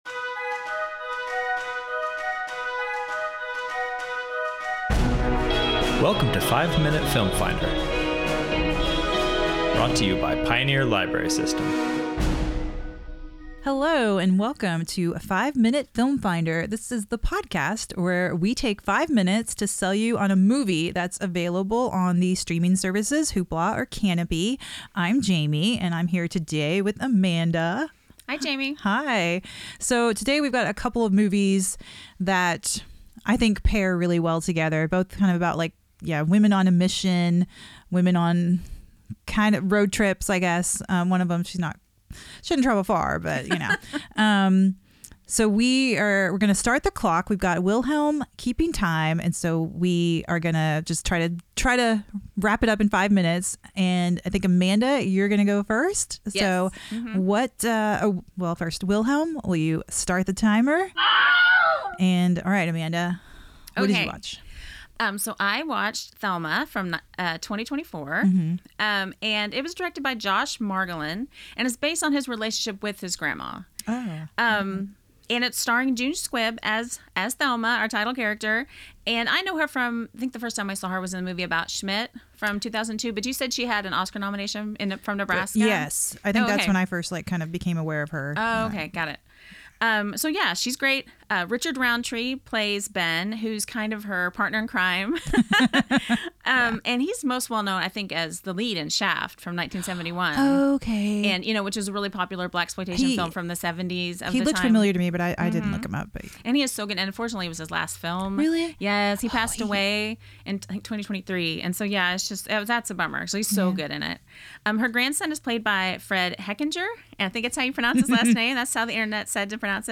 Our hosts have five minutes to inform and sell you on the movies covered in this episode.